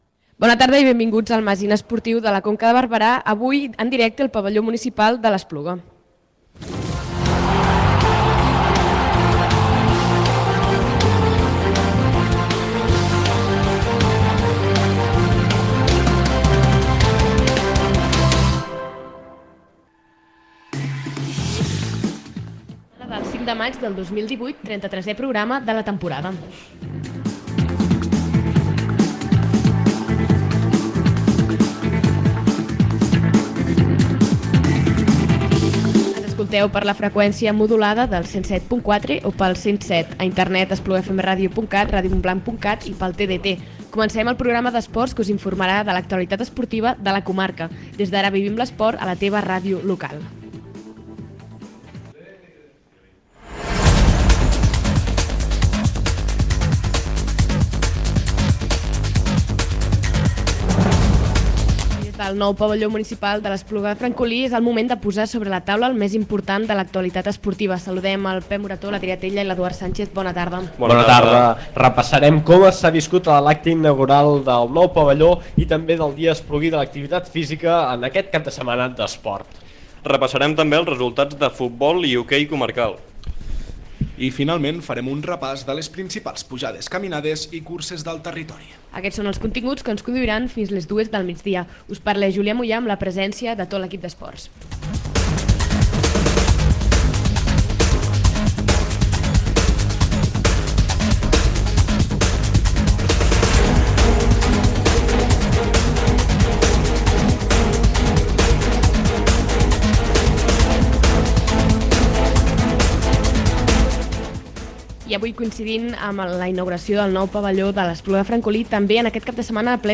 L’equip d’esports de l’Espluga Audiovisual i l’EFMR s’ha traslladat aquest dissabte al nou Pavelló Municipal d’Esports de l’Espluga de Francolí per viure la inauguració del nou equipament des de primera línia i explicar-lo a l’audiència. En aquesta edició, per tant, també escoltem les veus d’alguns dels protagonistes de la jornada, els quals ens parlen del nou pavelló. D’altra banda, també repassem els resultats dels clubs esportius de la comarca i les principals notícies d’aquesta setmana.